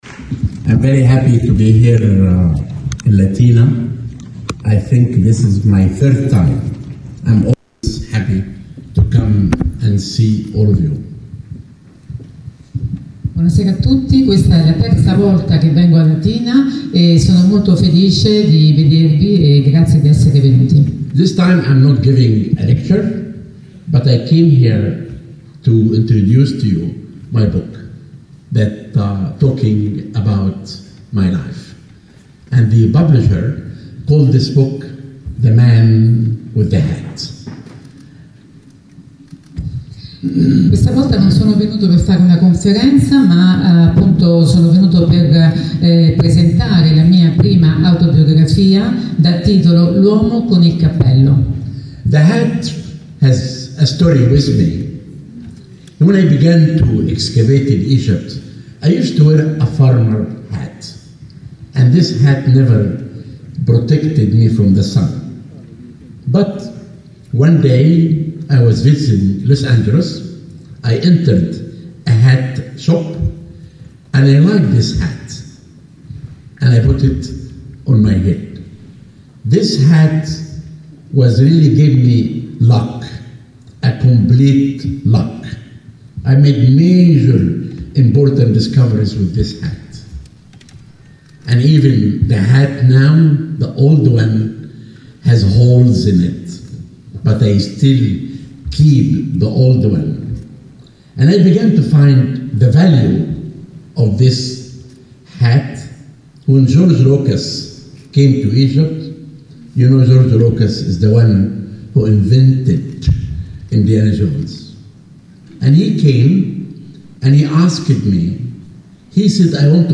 zahi_hawass_presentazione.mp3